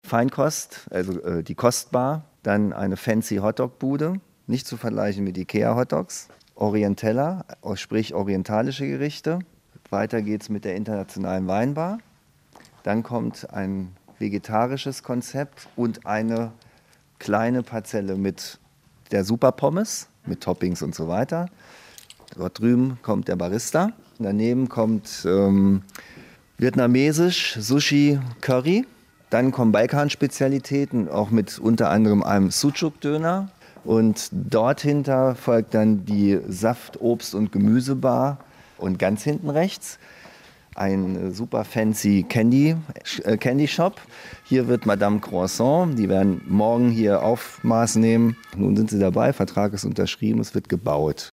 Rundgang durch die Markthalle